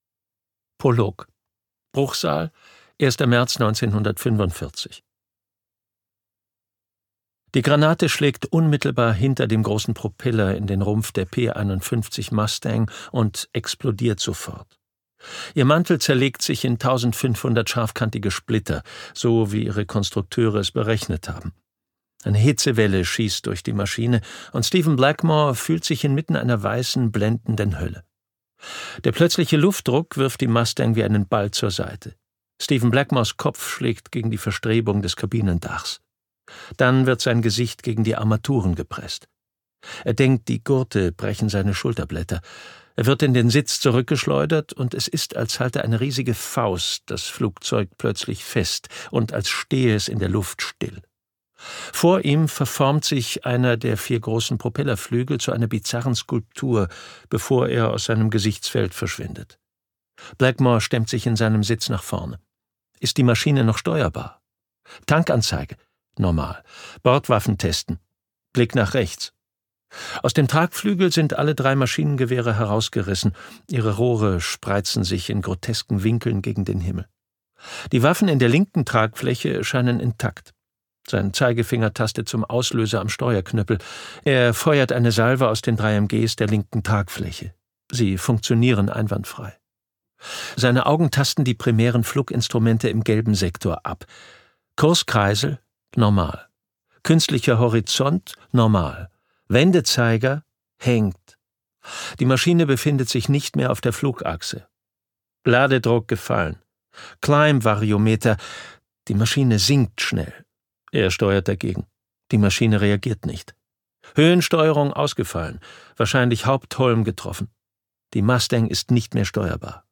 Das dunkle Schweigen - Wolfgang Schorlau | argon hörbuch
Gekürzt Autorisierte, d.h. von Autor:innen und / oder Verlagen freigegebene, bearbeitete Fassung.